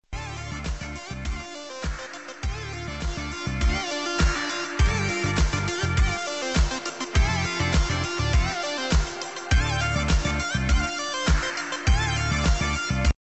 De voicerecorder neemt op met een ADPCM codec op 16 kHz, 4 bit op de microSD kaart (WAV bestanden).
Alleen de opnamekwaliteit is wel laag, vanwege de eerder genoemde instelling. Maar muziek wordt ondanks de lage kwaliteit, wel in stereo opgenomen.
Muziekopname voorbeeld: